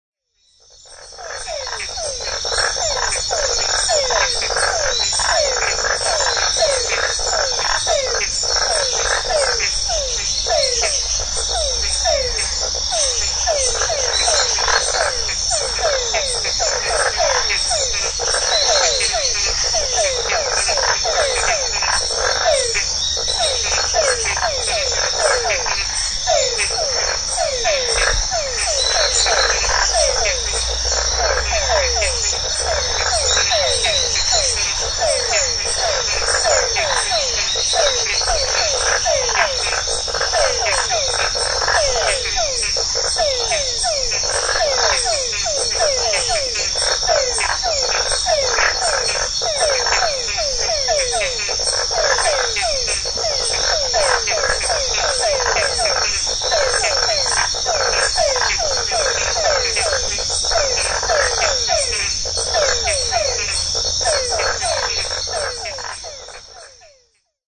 We invite you to a short trip into the fantastic world of sounds of the tropical rain forest and cloud forest.
Birds, frogs and many hidden insects are the musicians.
CASA MARIA: Concert of frogs at sunset.
frogs.mp3